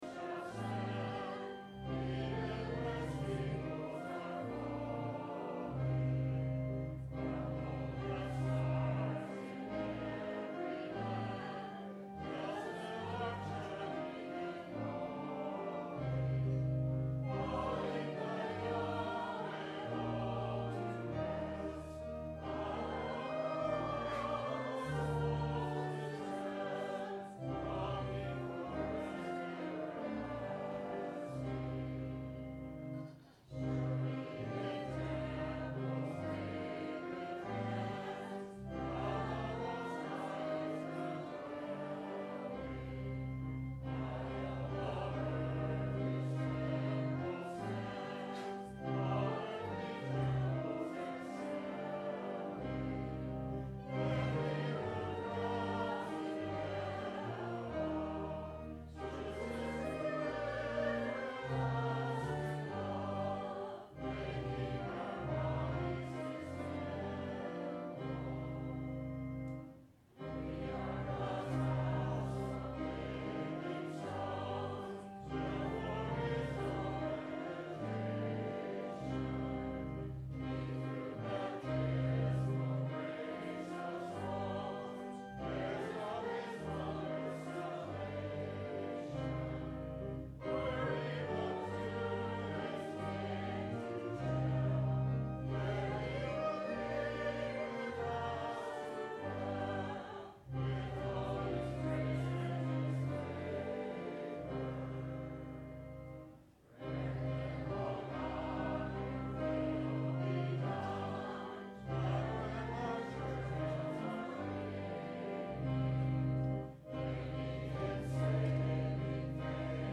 The Certainty of the Christian – Sermon – October 09 2011